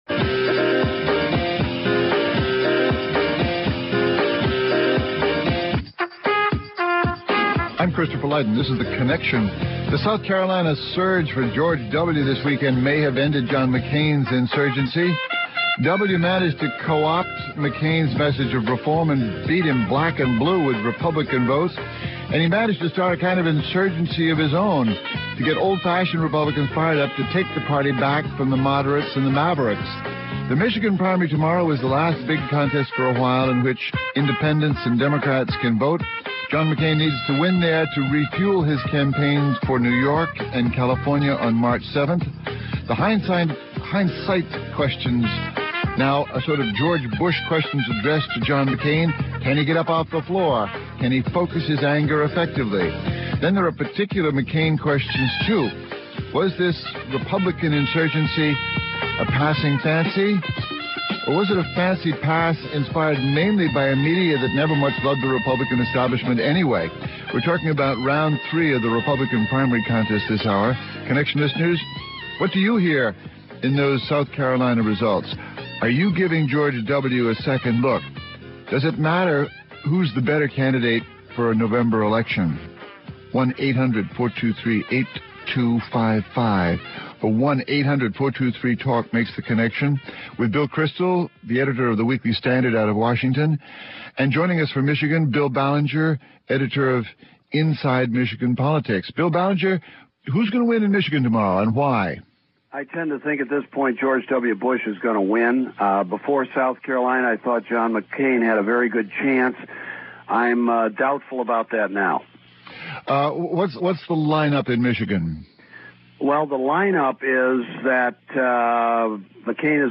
The President of the University of California, the largest state university system in the country, wants to scrap submission of S A T aptitude scores as a requirement for admission. On the east coast, officials at Harvard say the emphasis on S A T scores is just one part of an admissions process gone haywire. (Hosted by Christopher Lydon)